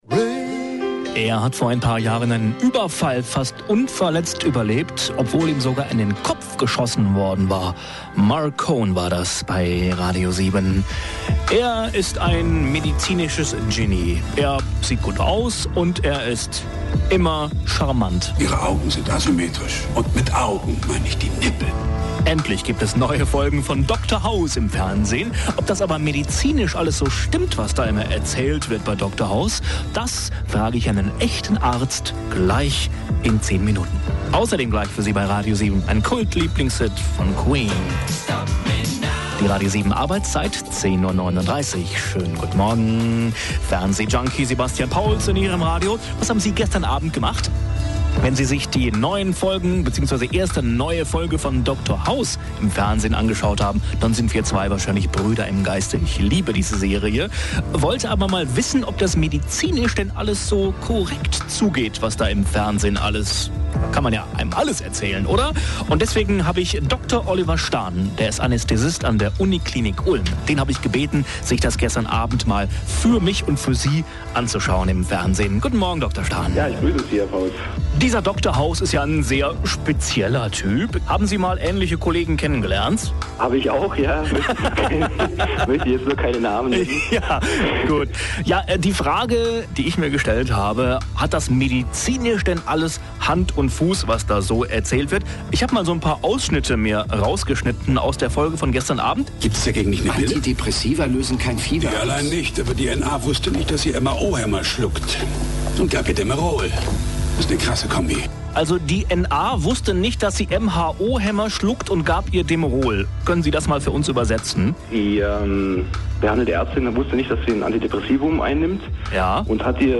bei mir in der Sendung...
house_mitschnitt.mp3